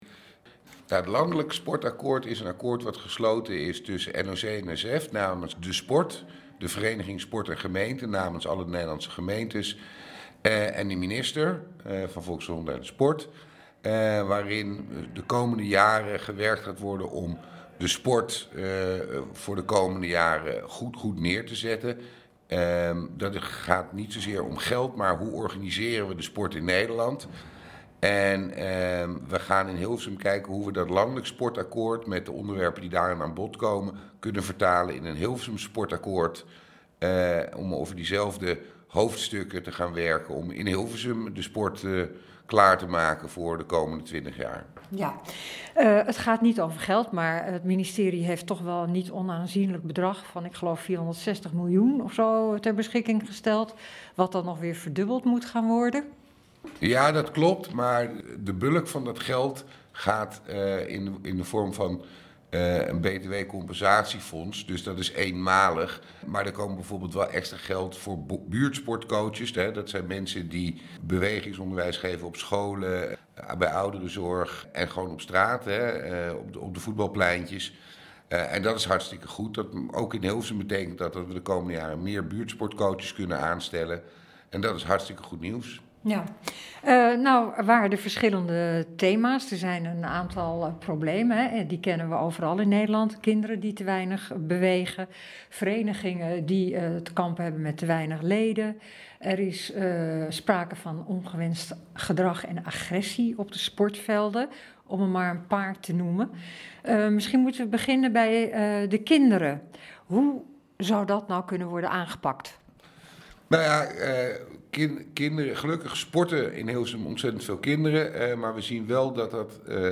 Een gesprek met wethouder Floris Voorink.